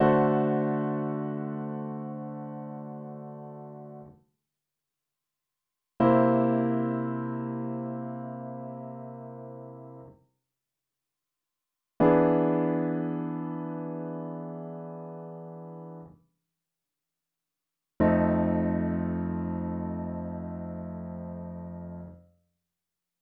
Dominant Sevenths – Open Voicing